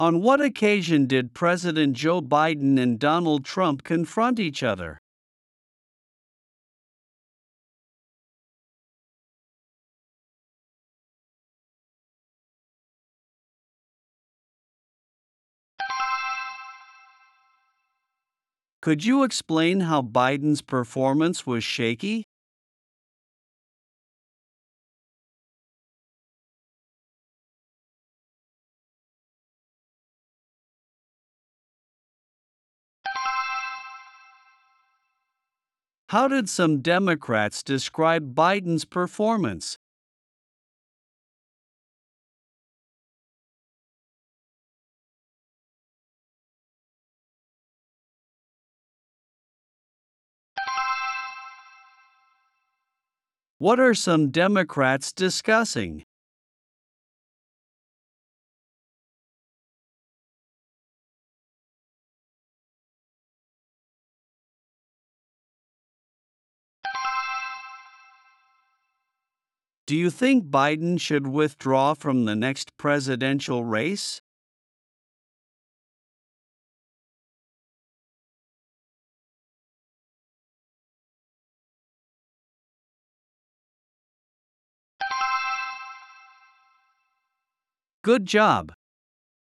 プレイヤーを再生すると英語で5つの質問が1問ずつ流れ、10秒のポーズ（無音部分）があります。
10秒後に流れる電子音が終了の合図です。
10秒スピーチ質問音声